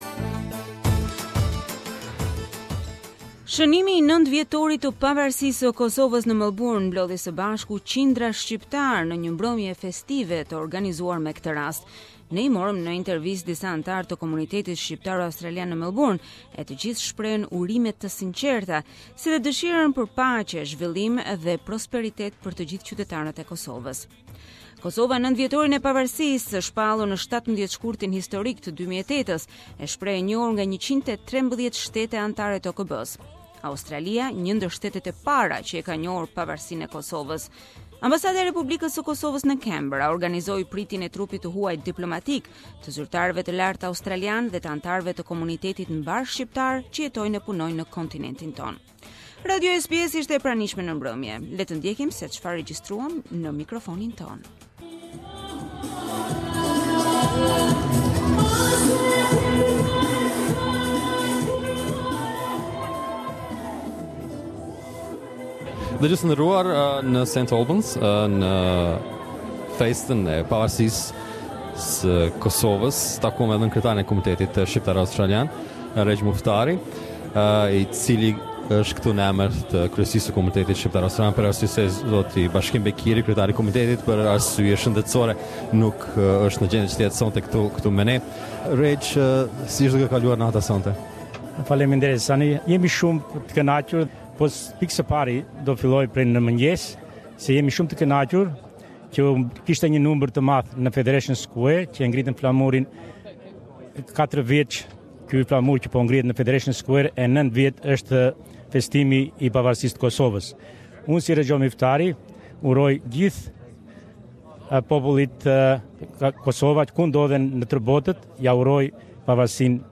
We interviewed some members of the Australian Albanian Community in Melbourne and all of them expressed their sincere congratulations and desire for peace, development and prosperity for all citizens of Kosovo.